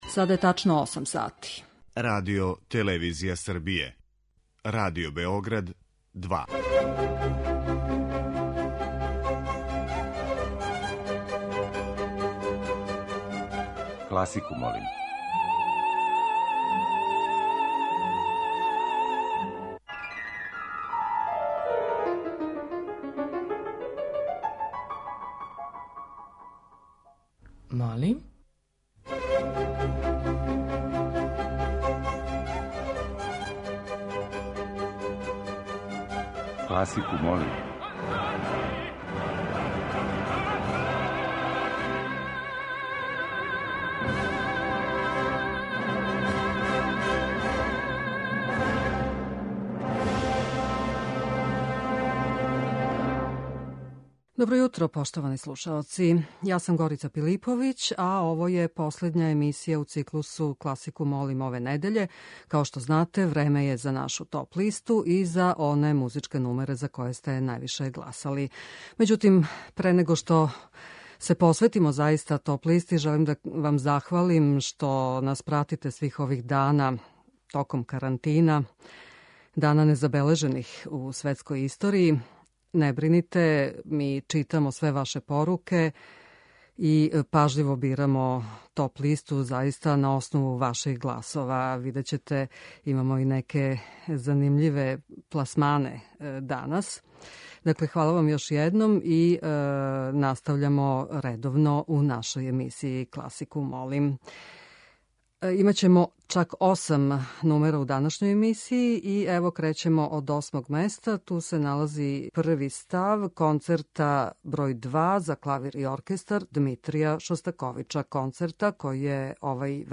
Оперске улоге лирских сопрана
Тема циклуса биће оперске улоге које тумаче лирски сопрани.